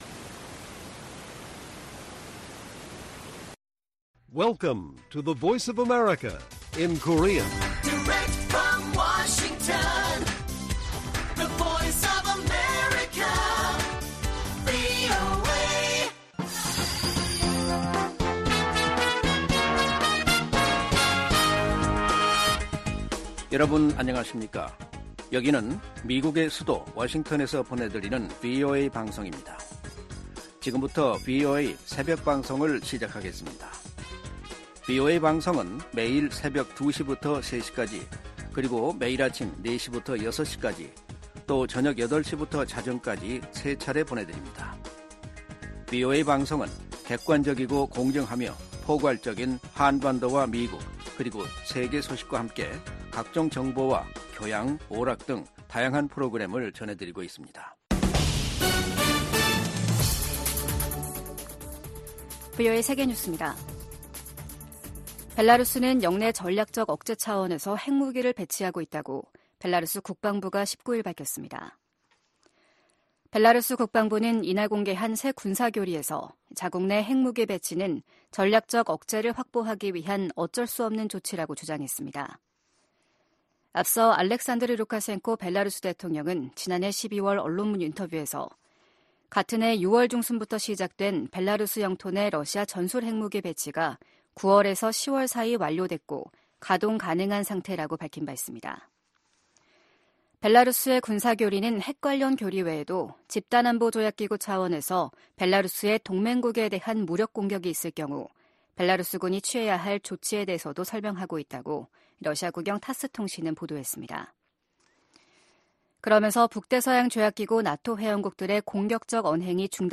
VOA 한국어 '출발 뉴스 쇼', 2024년 1월 20일 방송입니다. 북한이 수중 핵무기 체계 '해일-5-23'의 중요 시험을 동해 수역에서 진행했다고 발표했습니다. 유엔 안전보장이사회가 새해 들어 처음 북한 관련 비공개 회의를 개최한 가운데 미국은 적극적인 대응을 촉구했습니다. 최근 심화되는 북러 군사협력으로 향후 10년간 북한의 역내 위협 성격이 급격하게 바뀔 수 있다고 백악관 고위 당국자가 전망했습니다.